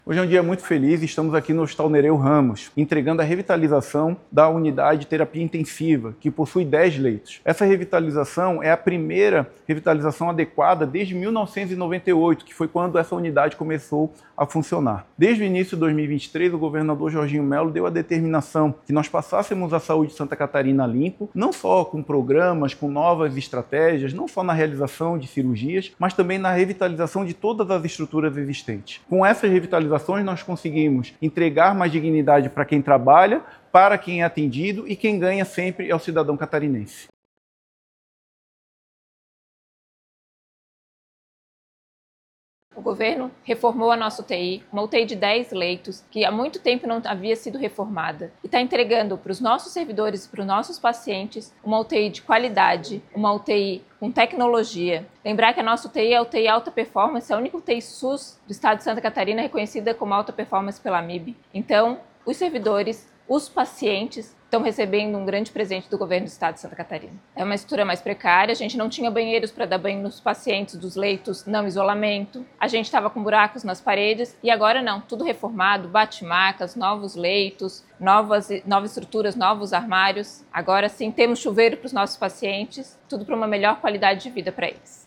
O secretário Diogo Demarchi destaca que esse é mais um passo para entregar mais dignidade para quem trabalha e para quem é atendido: